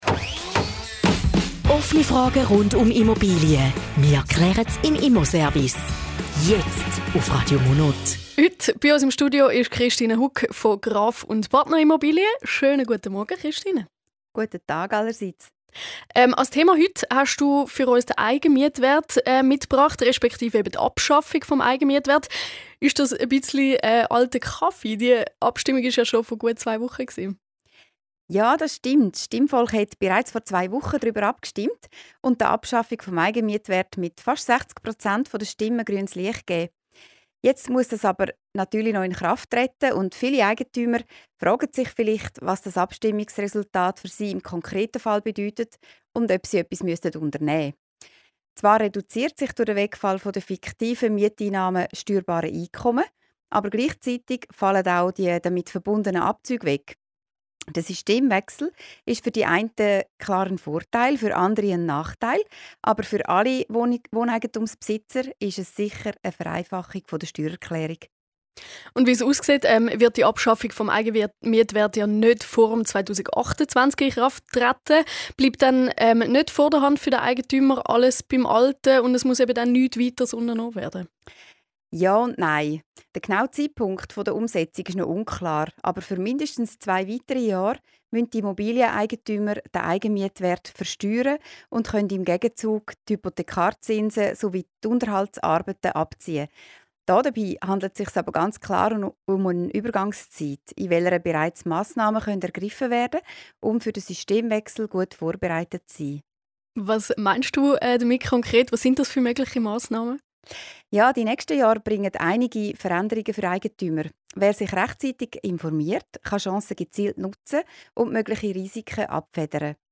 Zusammenfassung des Interviews zum Thema "Eigenmietwert abgeschafft; und was nun":